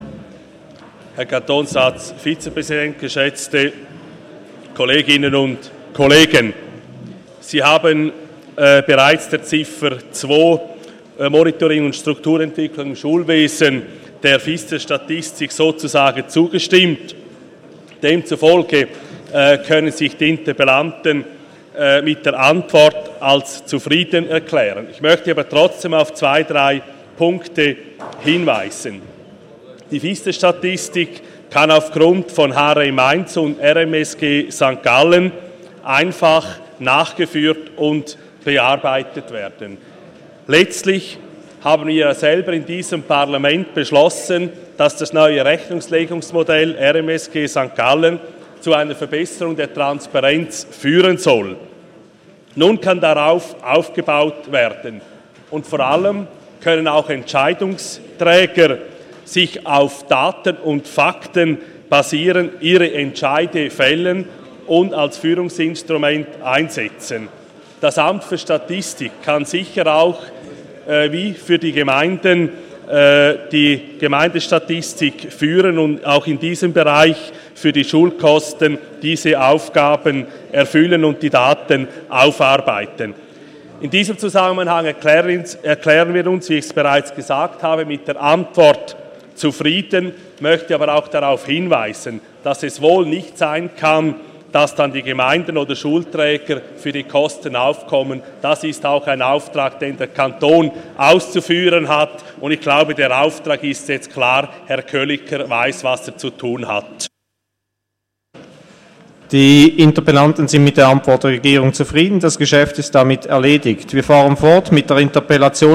25.4.2017Wortmeldung
Session des Kantonsrates vom 24. und 25. April 2017